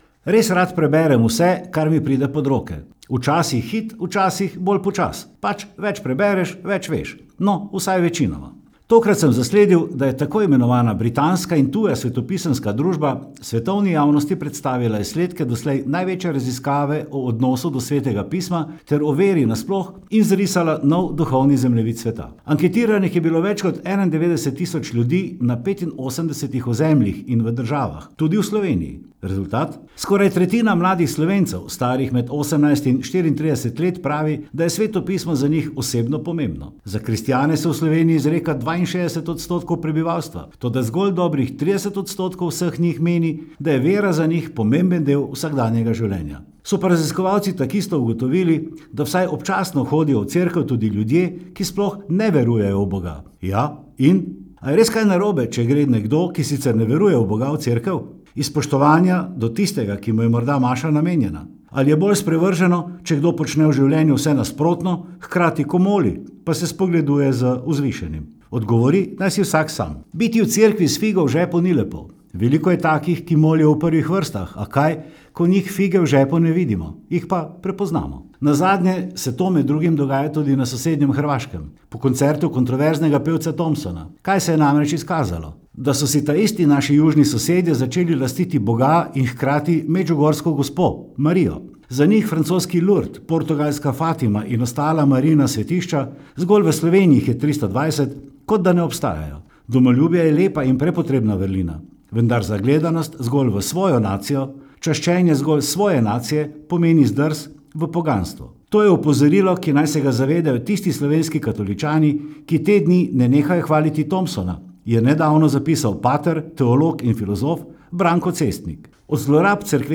komentar.mp3